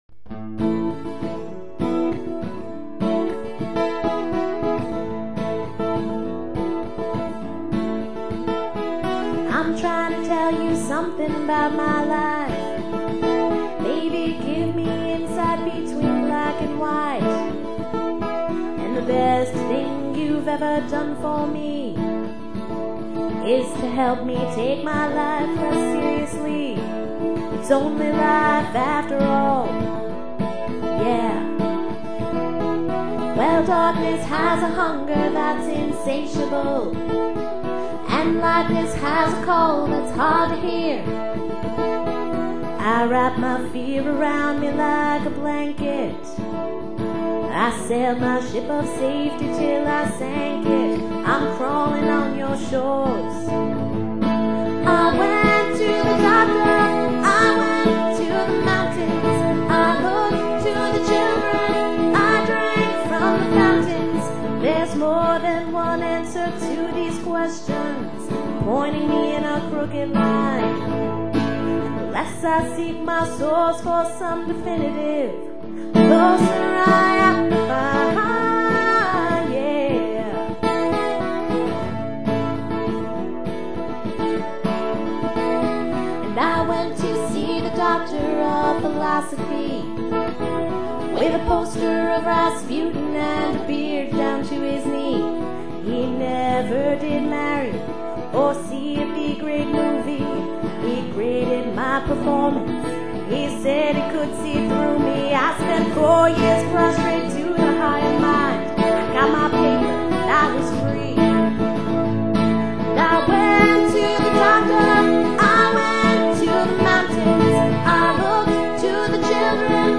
guitar
All songs recorded in May 2002 in Dallas, Texas